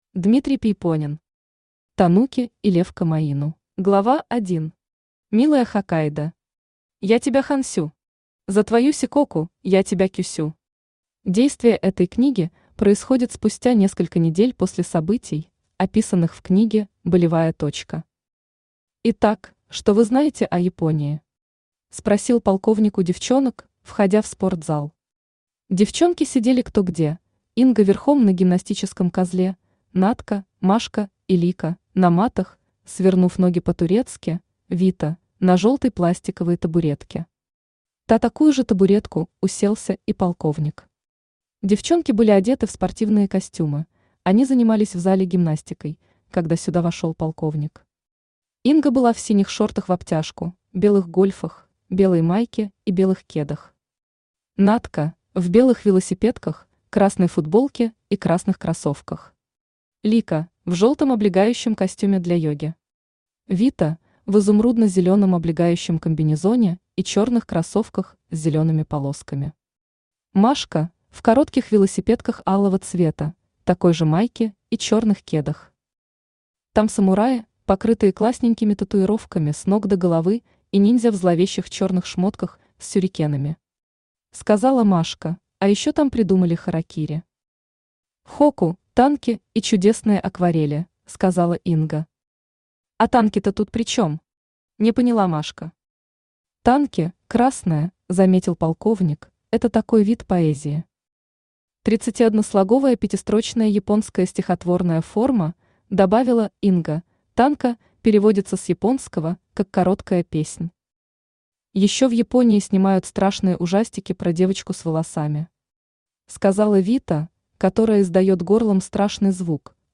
Аудиокнига Тануки и лев Комаину | Библиотека аудиокниг
Aудиокнига Тануки и лев Комаину Автор Дмитрий Пейпонен Читает аудиокнигу Авточтец ЛитРес.